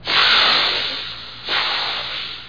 00163_Sound_cobra.mp3